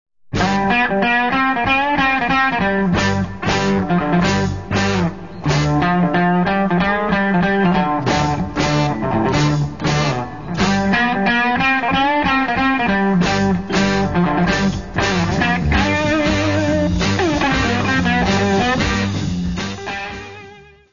: stereo; 12 cm + folheto
Music Category/Genre:  Pop / Rock